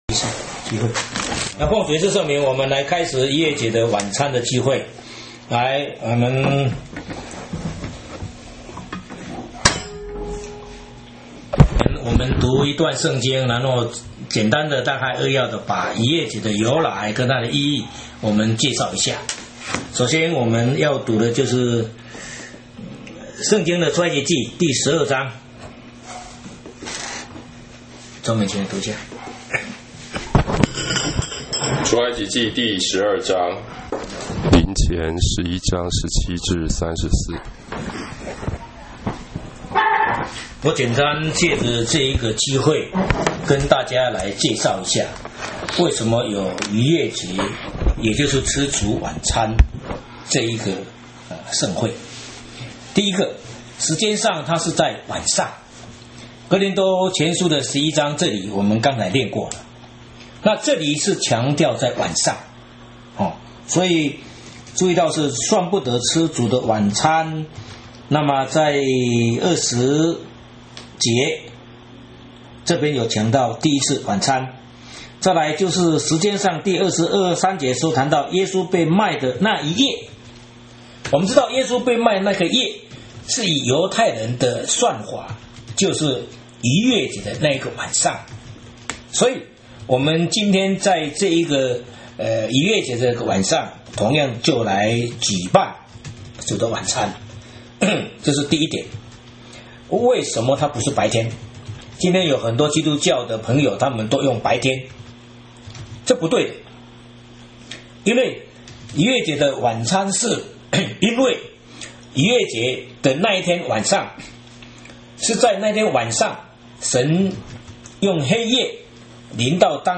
禱告